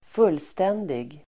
Uttal: [²f'ul:sten:dig]